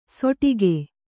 ♪ soṭige